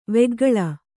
♪ veggaḷa